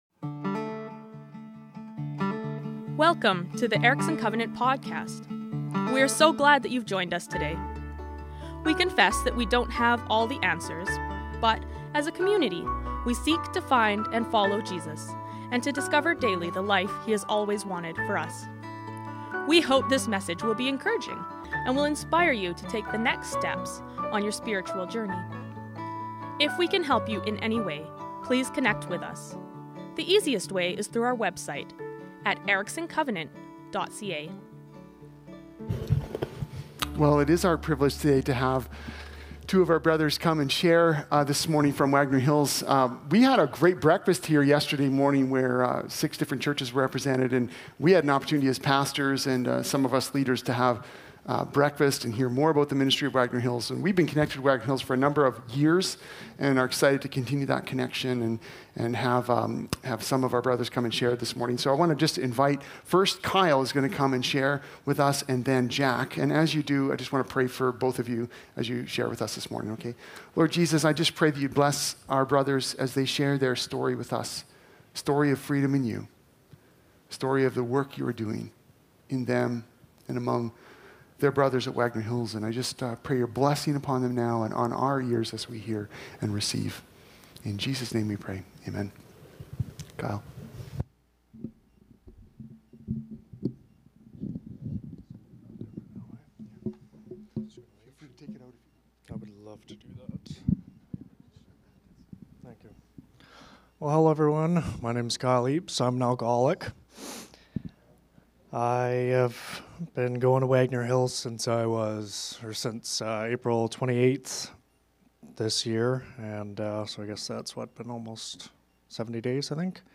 ECC Worship Gathering June 22, 2025.